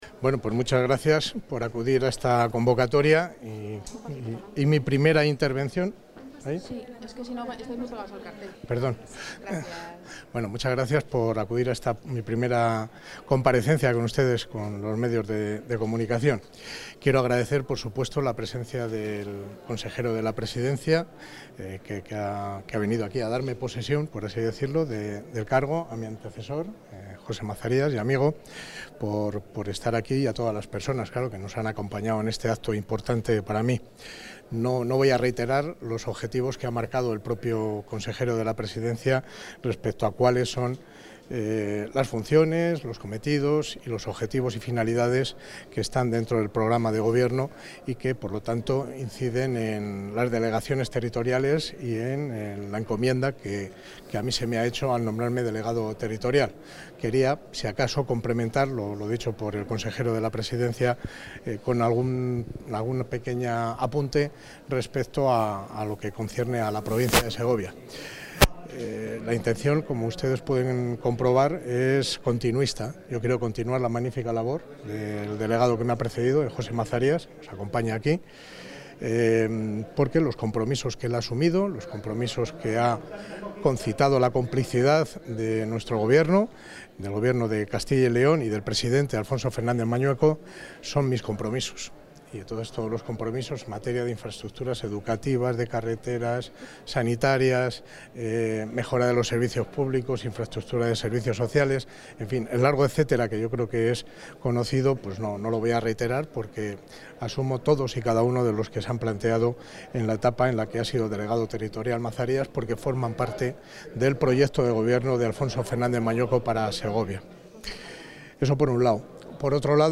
Intervención del consejero de la Presidencia.
El consejero de la Presidencia, Luis Miguel González Gago, ha presidido esta mañana en Segovia la toma de posesión del delegado territorial de la Junta en Segovia, José Luis Sanz Merino.